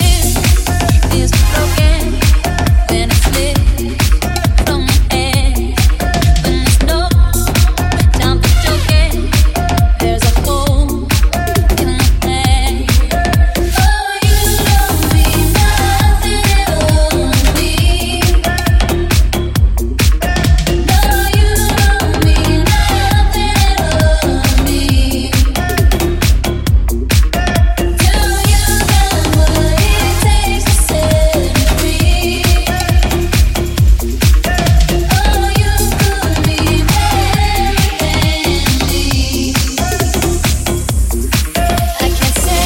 international famous hits remixed